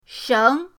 sheng2.mp3